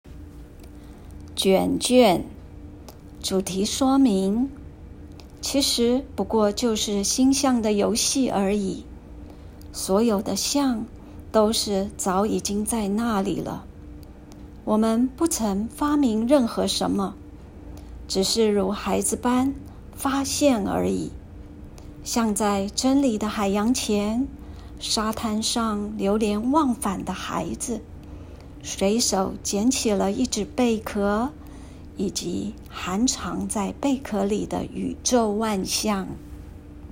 語音導覽，另開新視窗